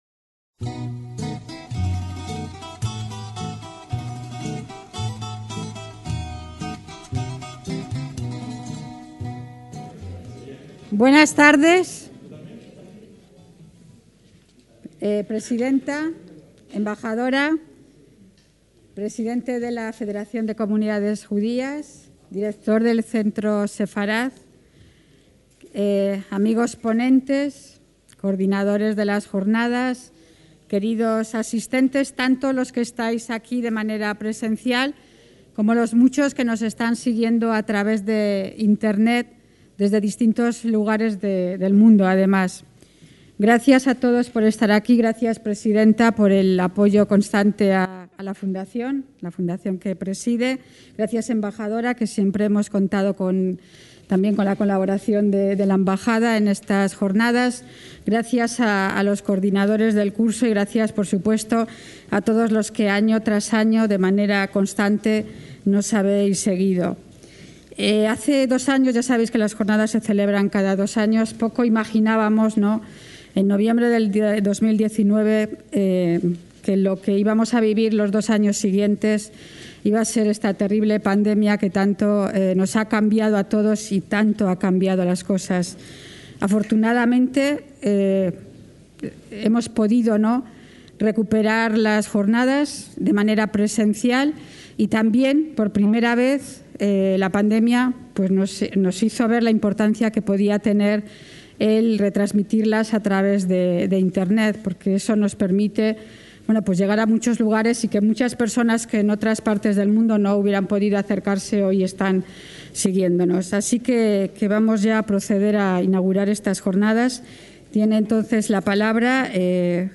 Sesión inaugural
JORNADAS SEFARDÍES EN SAN MILLÁN DE LA COGOLLA